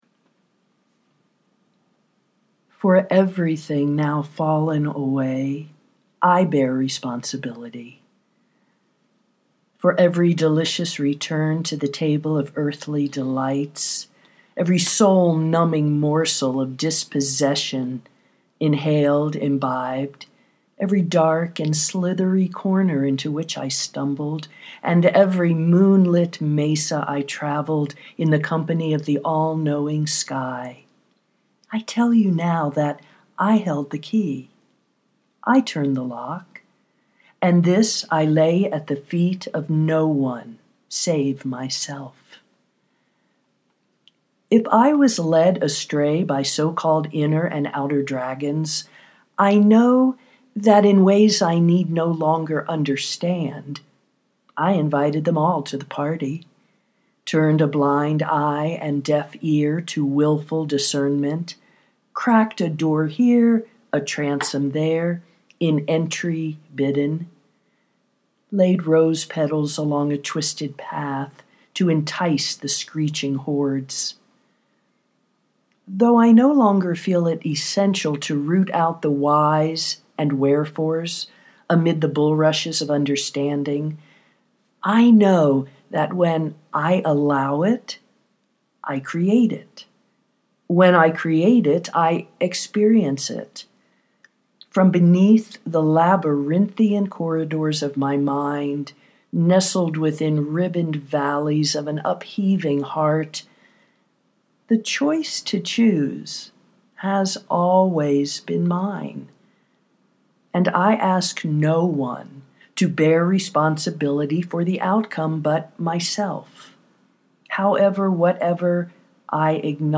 personal declaration of radical responsibility (audio poetry 4:29)